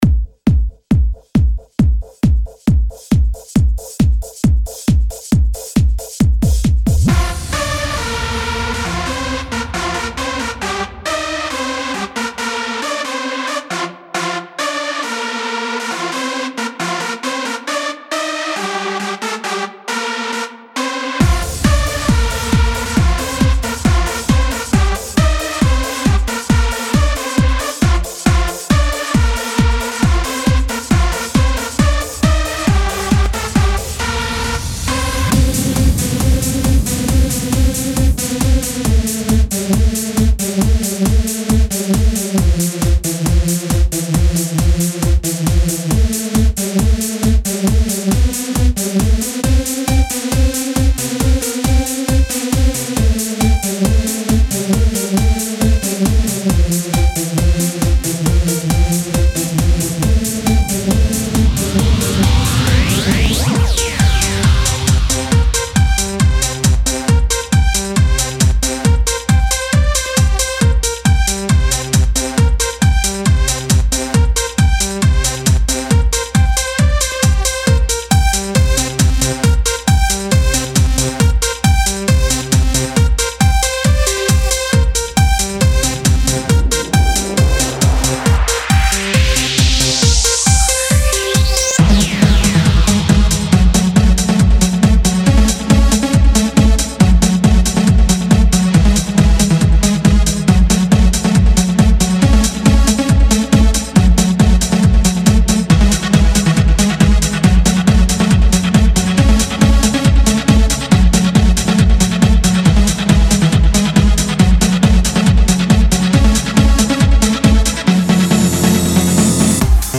uplifting hooks and melodies.
Synth and Lead Loops ONLY.